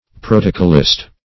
Protocolist \Pro"to*col`ist\, n. One who draughts protocols.